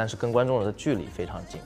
Chinese_Audio_Resource / 蔡徐坤 /有背景音乐的声音 /但是跟观众的距离非常近.wav